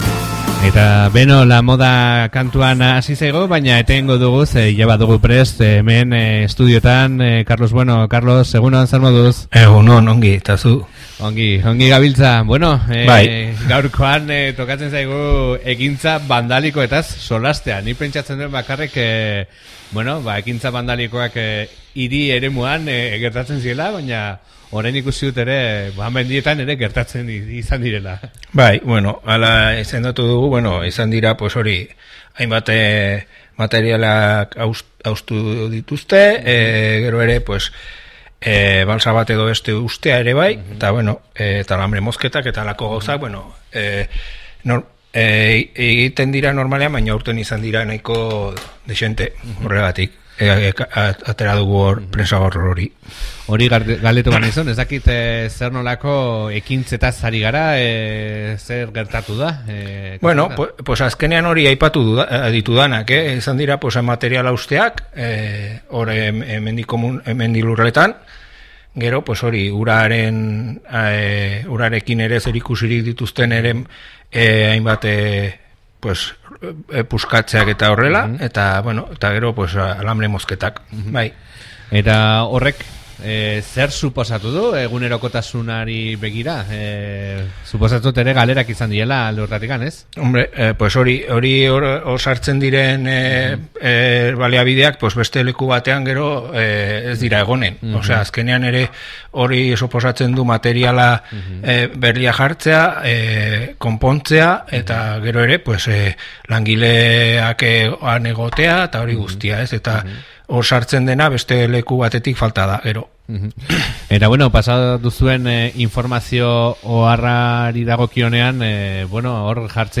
Gaiaz aritu gara Aezkoako Batzarreko lehendakaria den Karlos Buenorekin zeina adierazi digun ostiral honetan Batzarre batera deitu dutela gai hau jorratzeko.